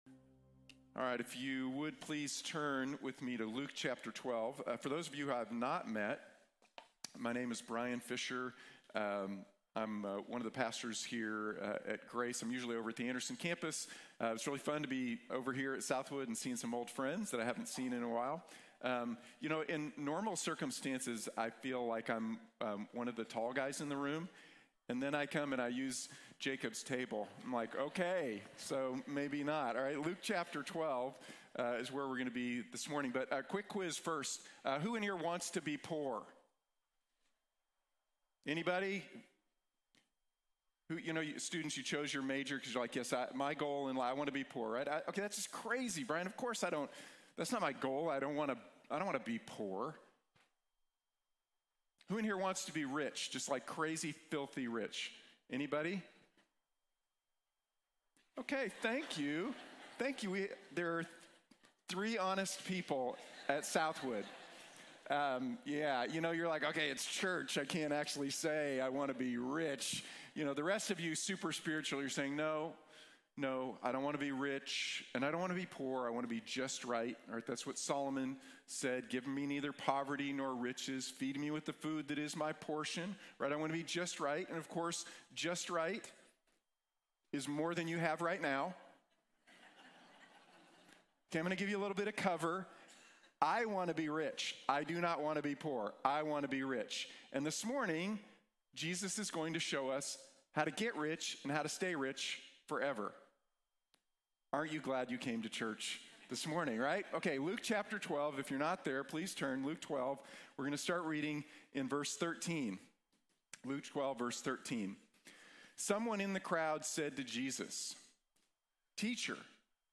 Invest in Eternity | Sermon | Grace Bible Church